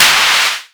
edm-clap-19.wav